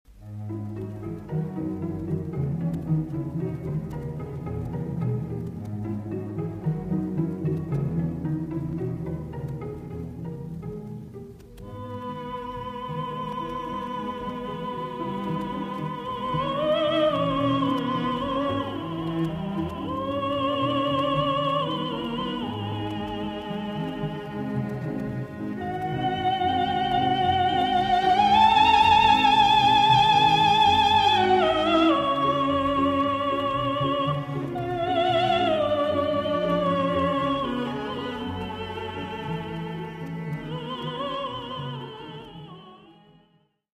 сопрано
виолончель